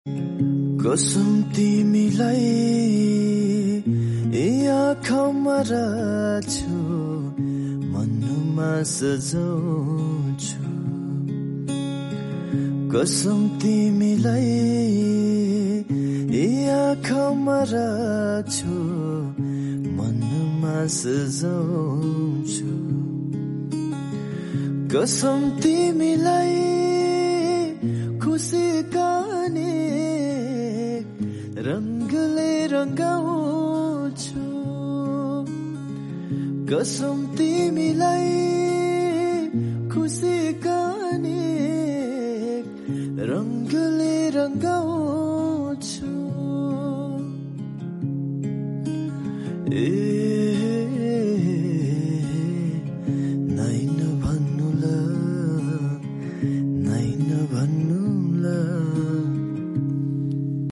raw cover